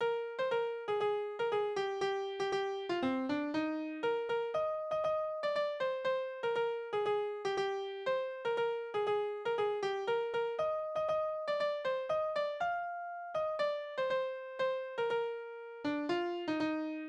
Tonart: Es-Dur
Taktart: 2/4
Tonumfang: Oktave, Quarte
Besetzung: vokal